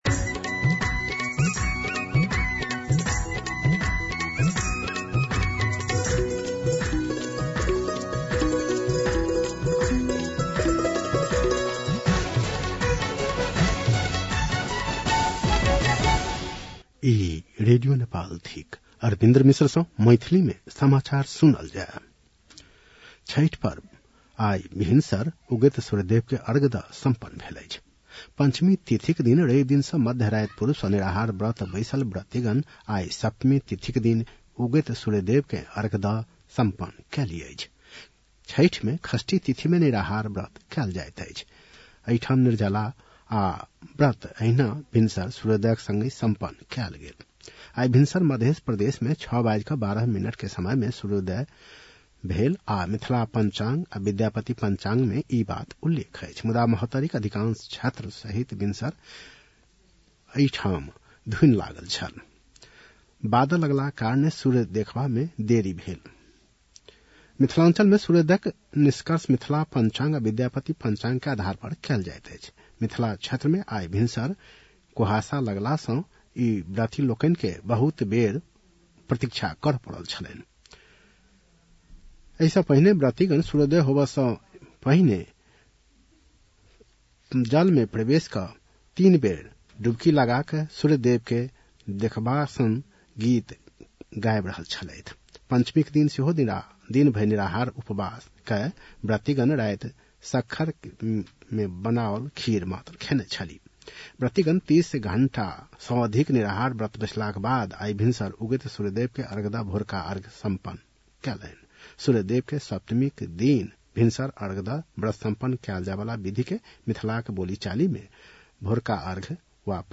मैथिली भाषामा समाचार : ११ कार्तिक , २०८२